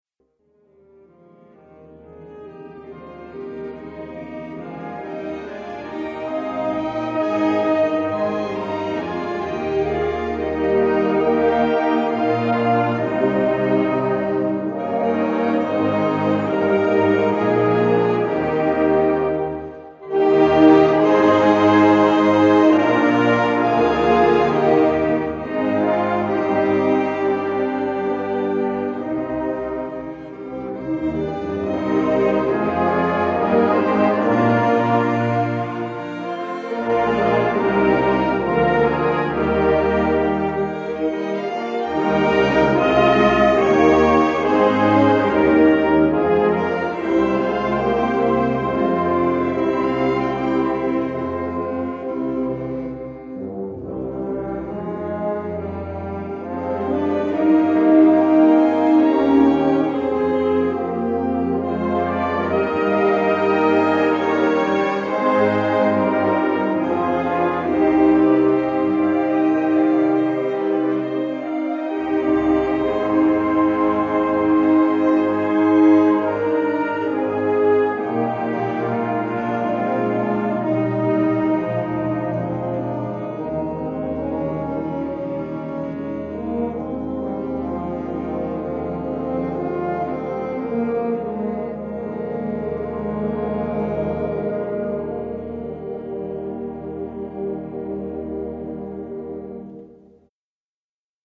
Gattung: Choralkantate
Besetzung: Blasorchester